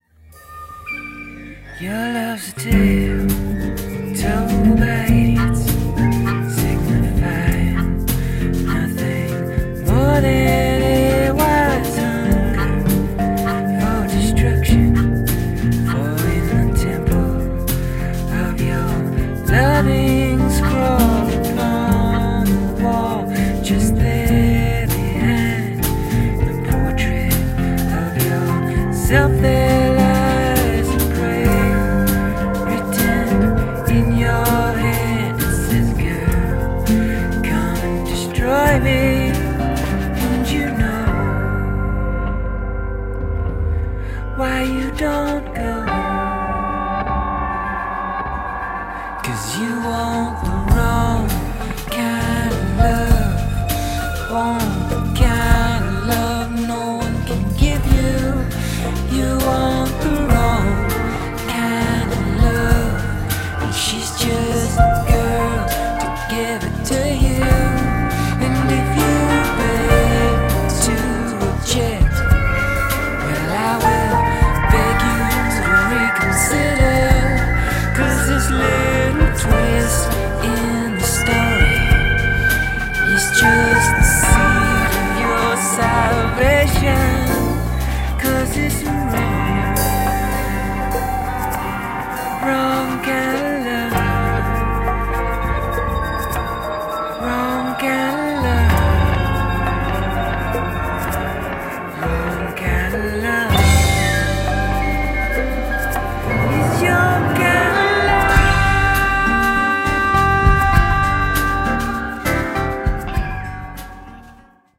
southern gothic folk funk